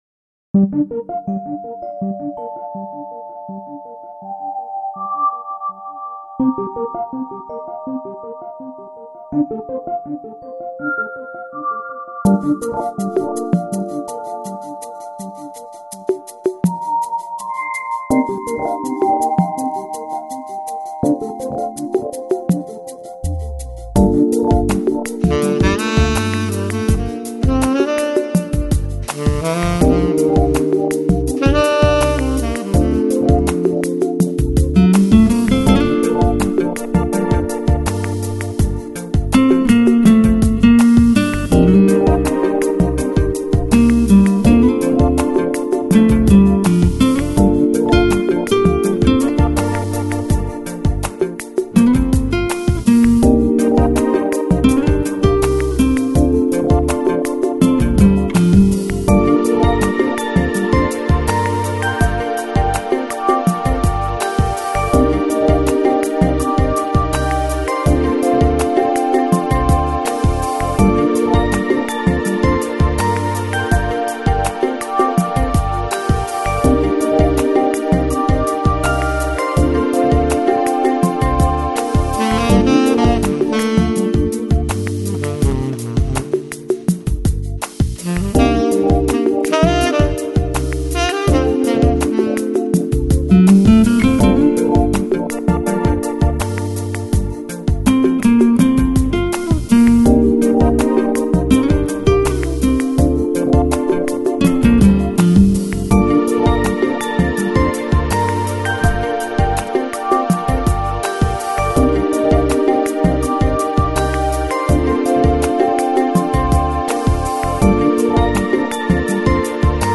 Жанр: Lounge, Chill Out, Smooth Jazz, Easy Listening